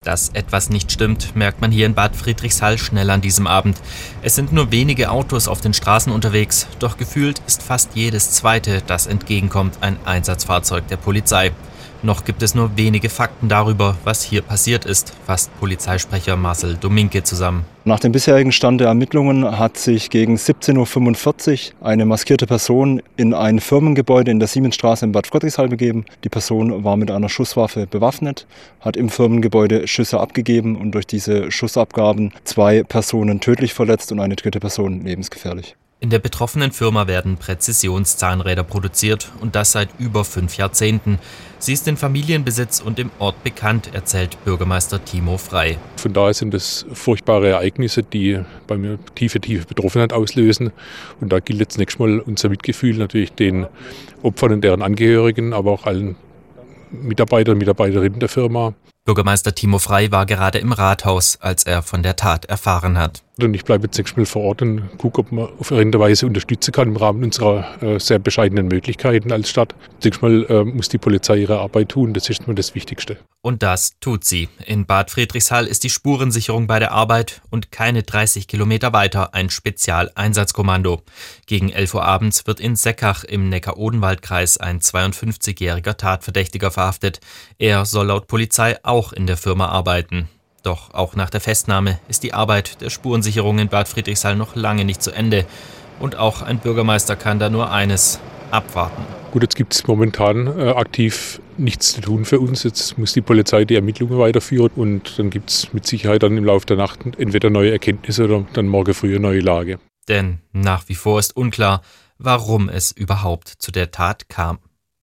Eindrücke und Stimmen von unserem Reporter